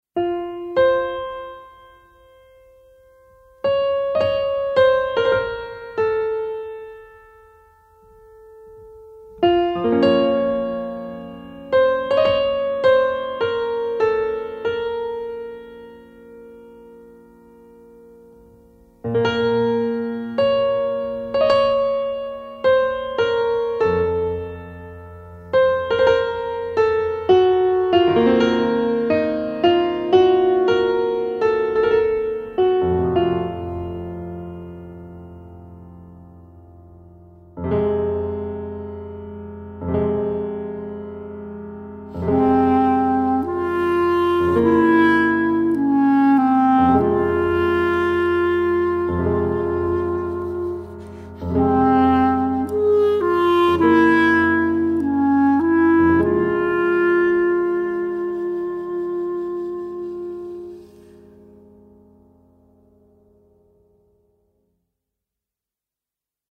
Piano
Clarinette
Flûte
Contrebasse
Accordéon
Guitare et Basse
Batterie